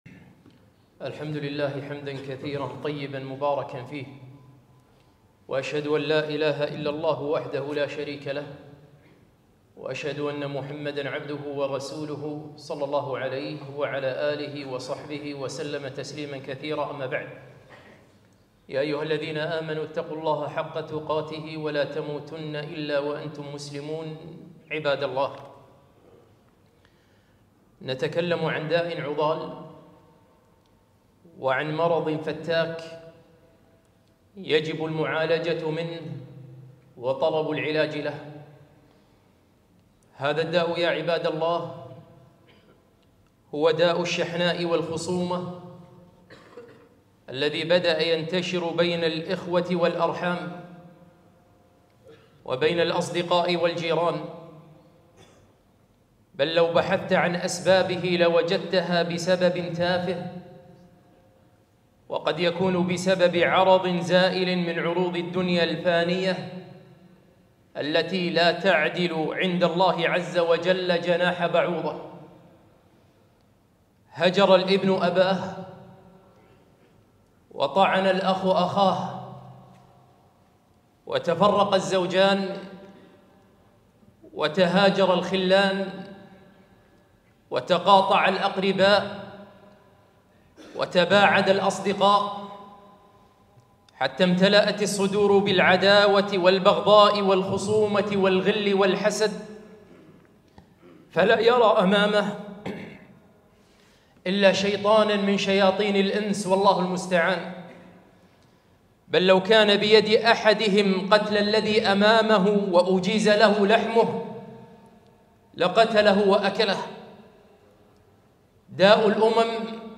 خطبة - ابتعد عن هذه الصفات